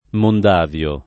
[ mond # v L o ]